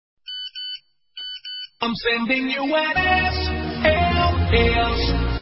/32kbps) 16kbps (10.6кб) Описание: Звук для смс ID 327527 Таги: sms Этот файл в ФО Просмотрен 3915 раз Скачан 1729 раз Скопируй ссылку и скачай Fget-ом в течение 1-2 дней!